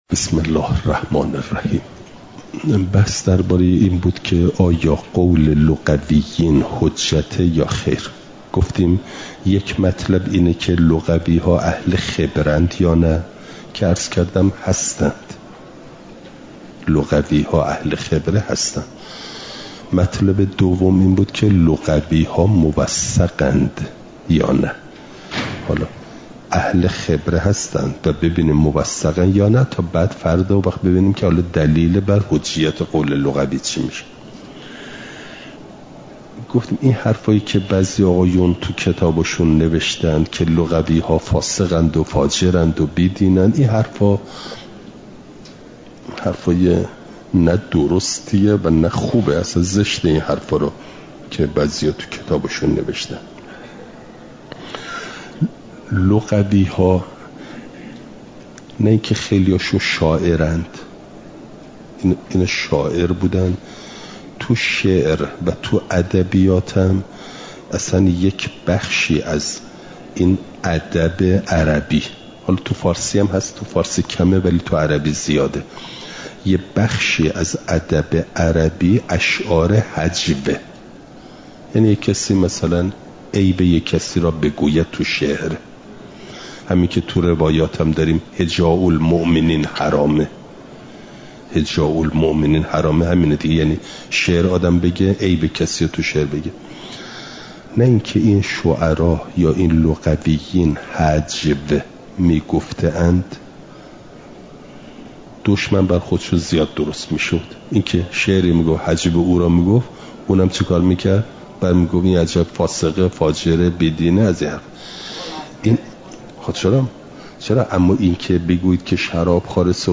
امارات؛ قطع و ظن (جلسه۷۸) – دروس استاد